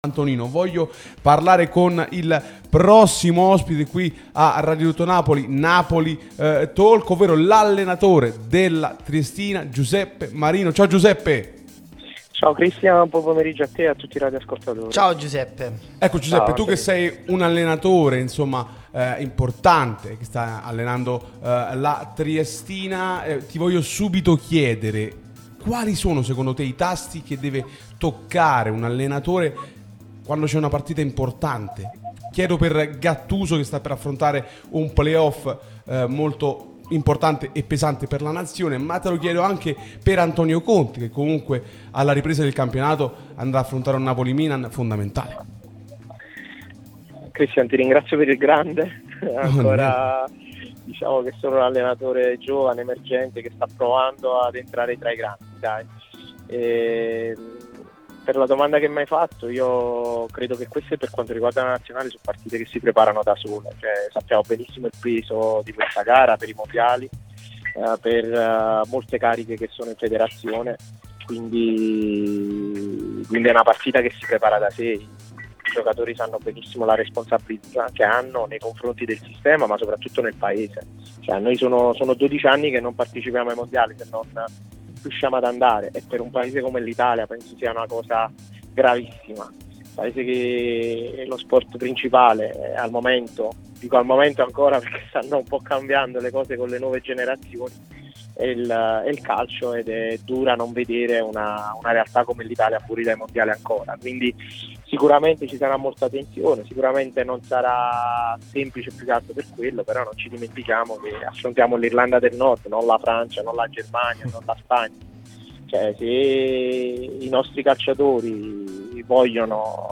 l'unica radio tutta azzurra e live tutto il giorno
Radio Tutto Napoli